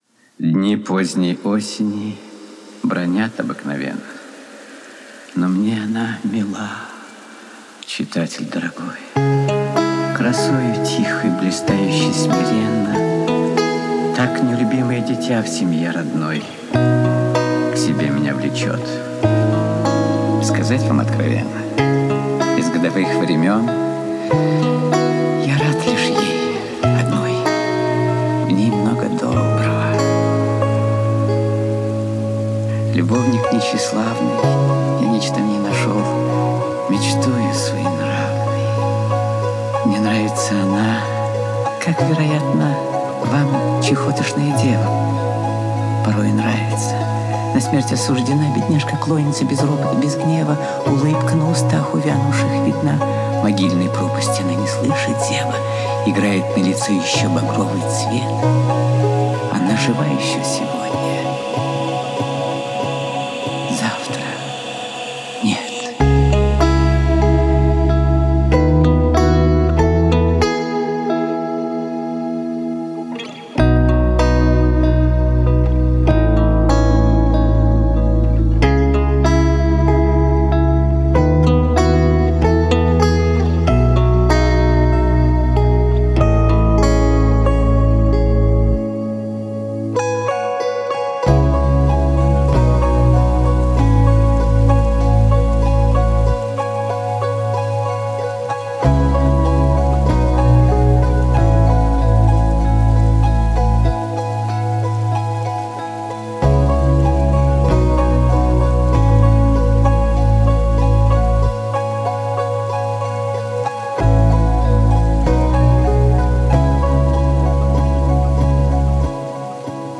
стихи А.С Пушкин / читает И.Смоктуновский .